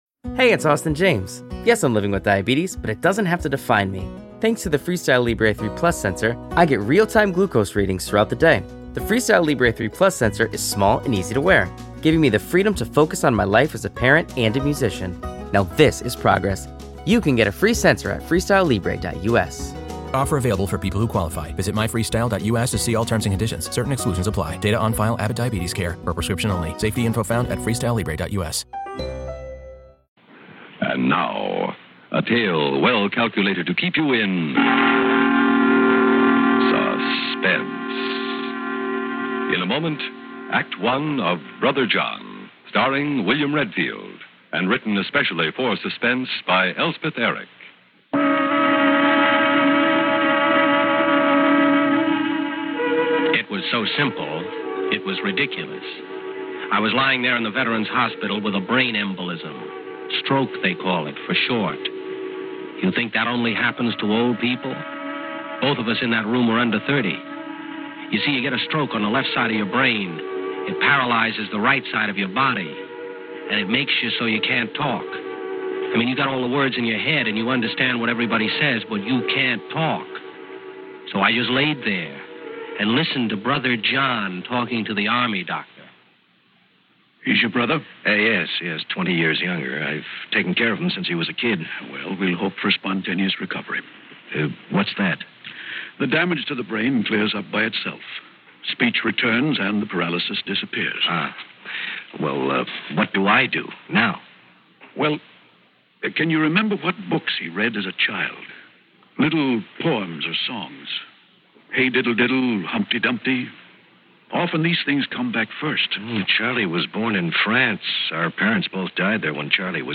On this episode of the Old Time Radiocast we present you with two stories from the classic radio program Suspense!